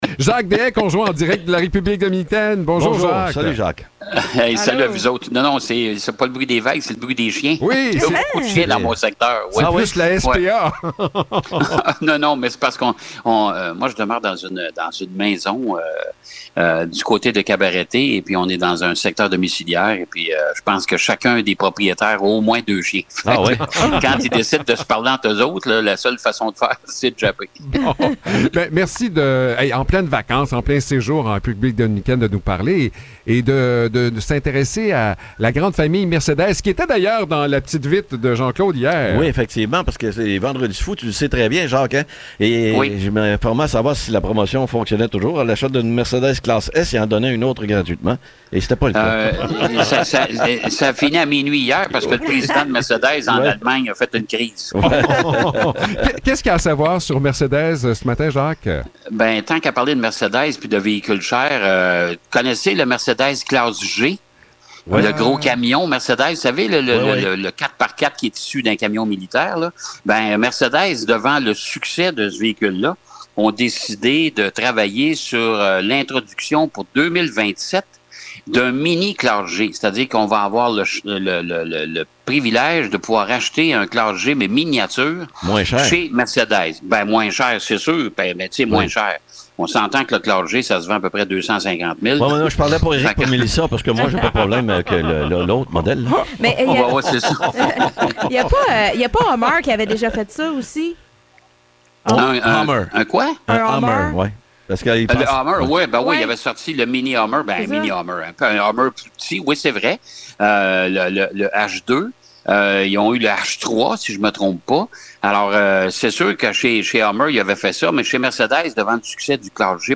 en direct de la République dominicaine